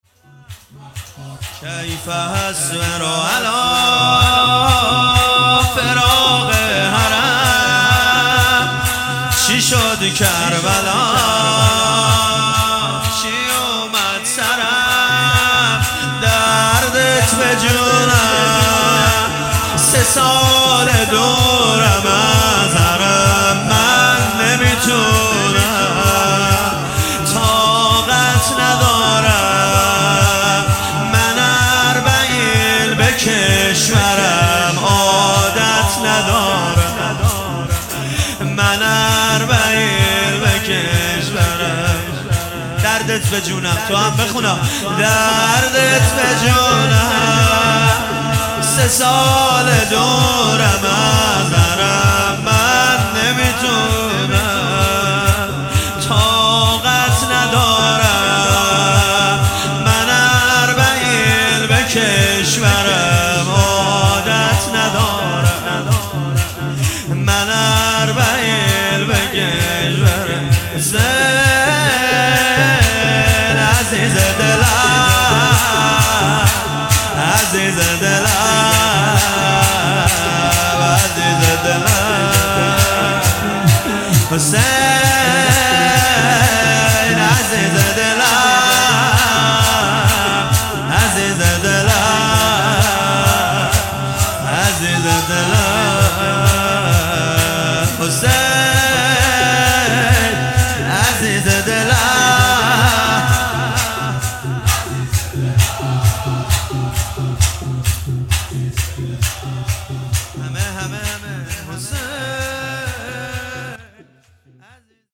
نوحه و مداحی